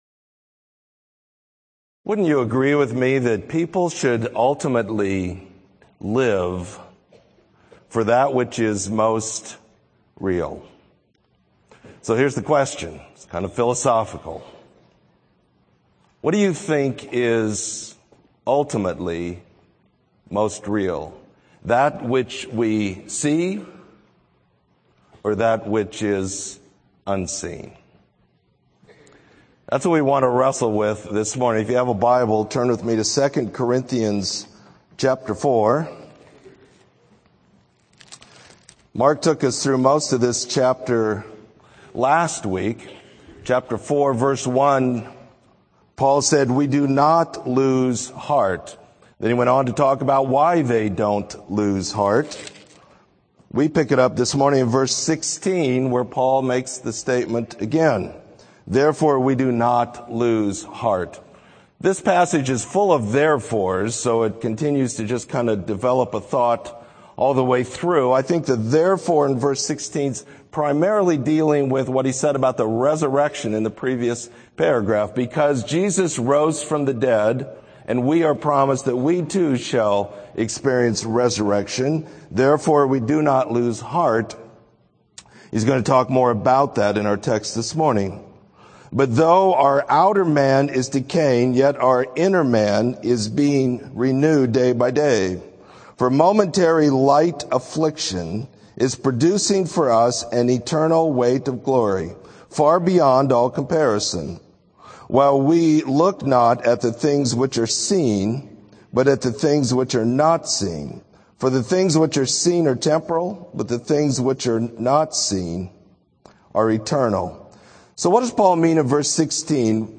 Sermon: Eternal Rewards